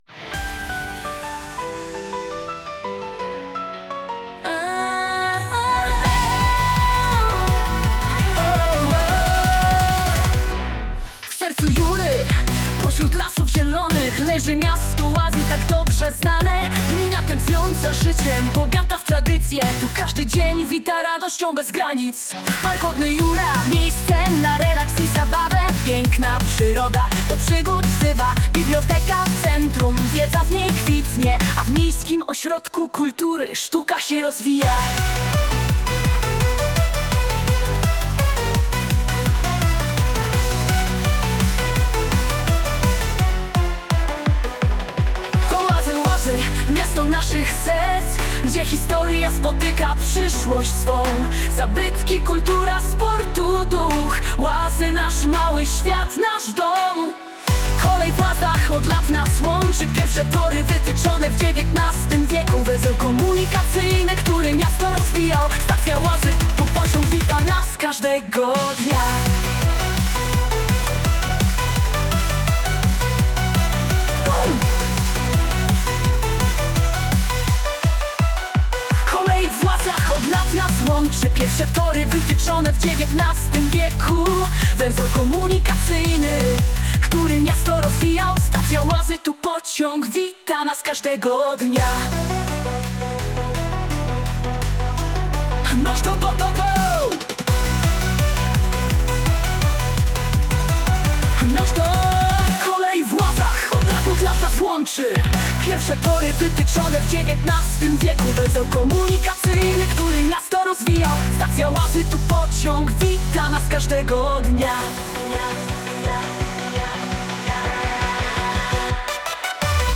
j-pop version ft.